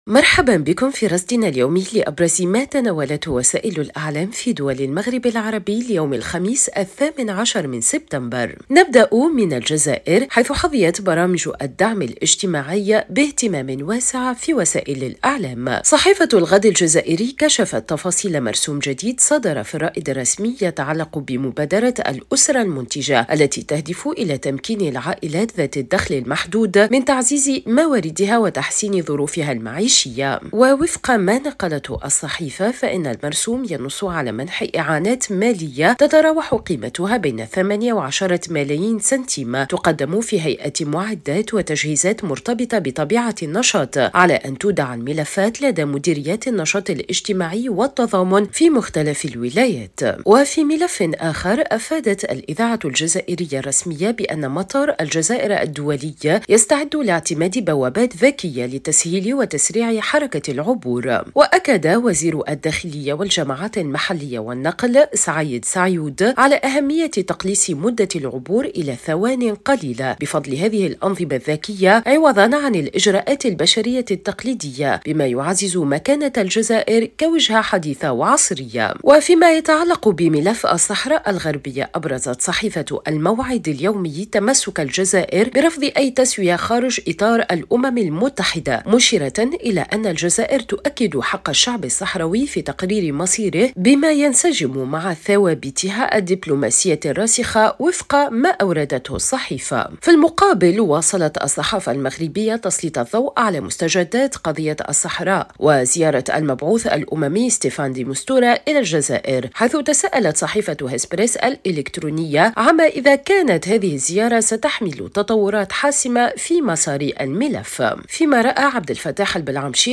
صدى المغرب برنامج إذاعي يومي يُبث عبر إذاعة الشرق، يسلّط الضوء على أبرز ما تناولته وسائل الإعلام في دول المغرب العربي، بما في ذلك الصحف، القنوات التلفزية، والميديا الرقمية.